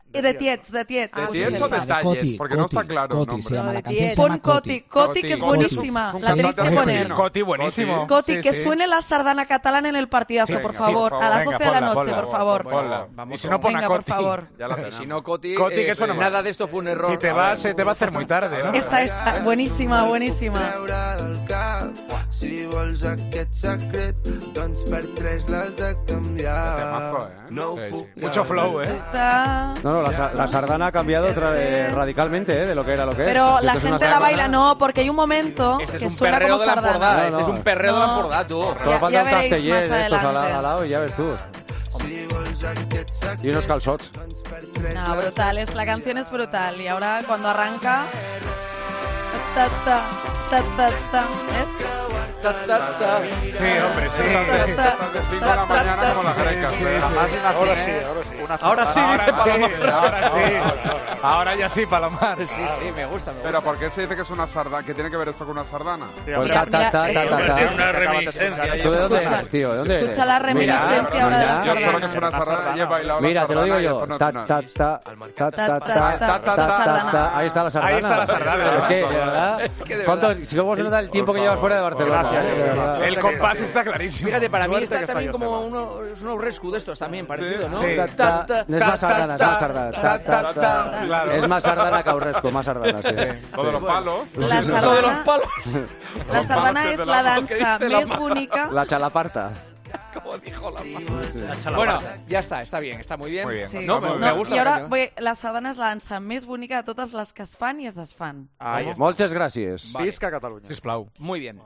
Juanma Castaño reacciona al escuchar por primera vez la canción COTI X COTI de The Tyets